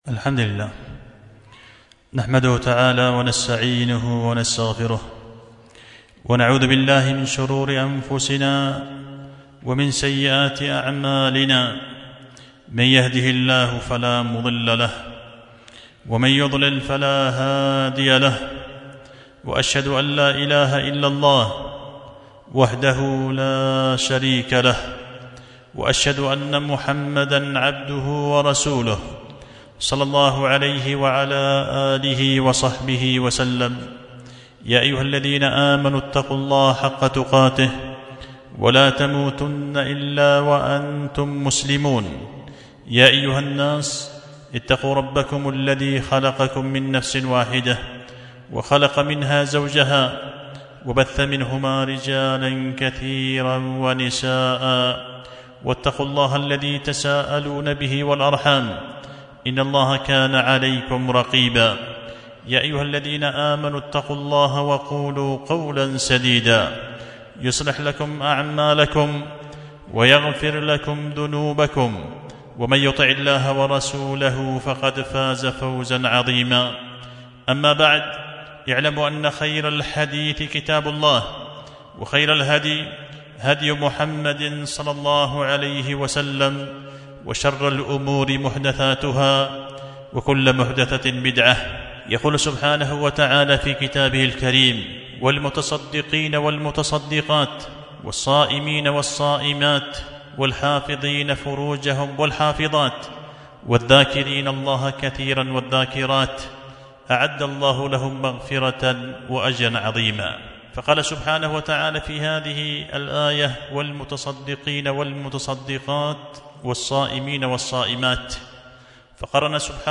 خطبة جمعة مفرغة بعنوان:(تحفة المتقين على قوله تعالى: والمتصدقين)